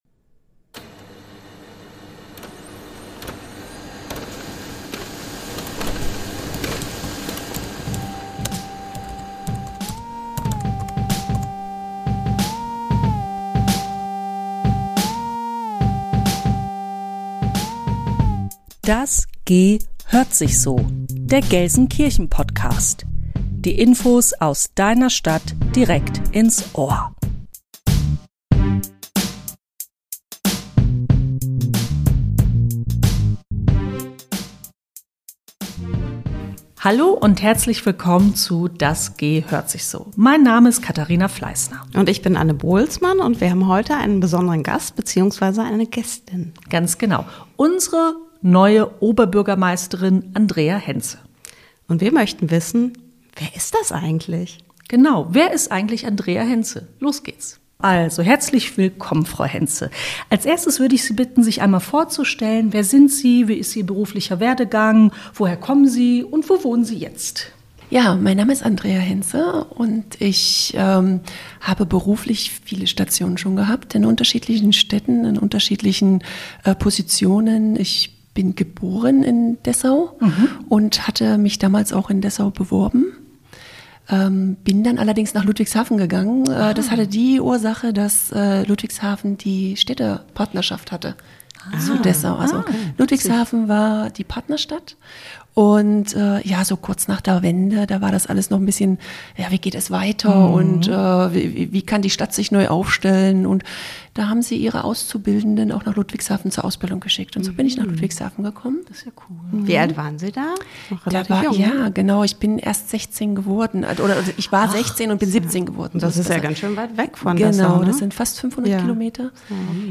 #24 Der Mensch Andrea Henze - die neue Oberbürgermeisterin im Interview ~ Das GEhört sich so. Der Gelsenkirchen-Podcast. Podcast